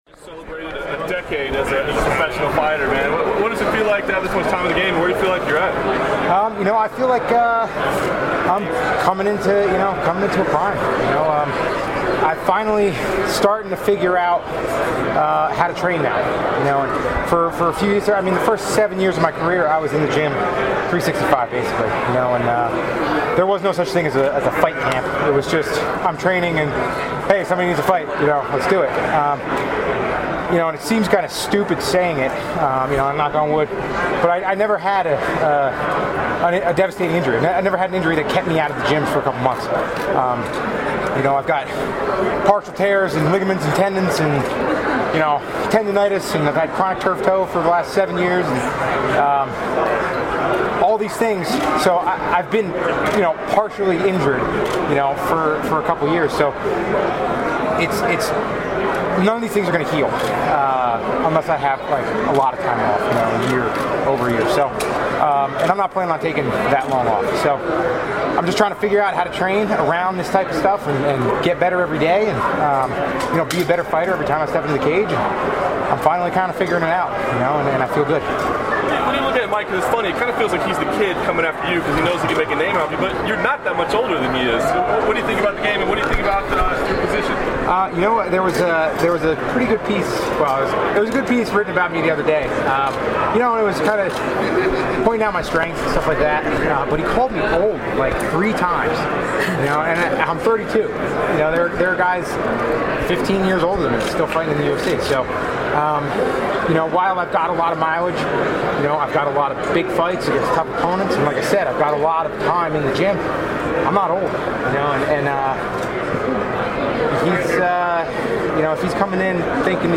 UFC lightweight contender Jim Miller chats to the press straight after completing his open workout session on the casino floor of the MGM Grand in Las Vegas.